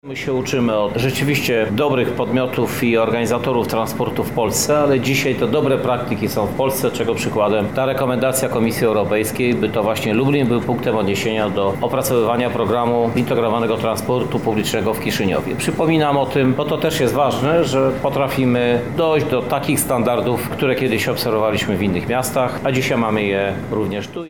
– mówi Krzysztof Żuk, prezydent Lublina